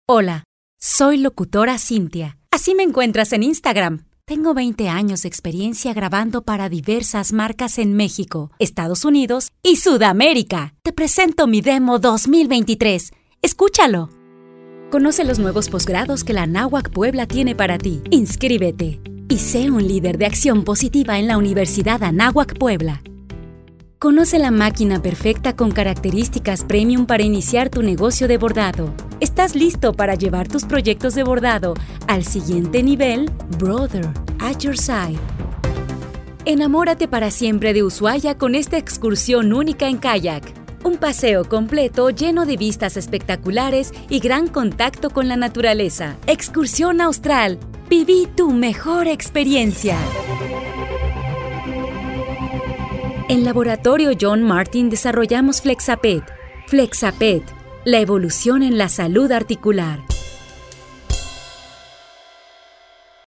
Variedad de estilos. Perfil de voz medio bajo. Amable, institucional, reflexivo y comercial.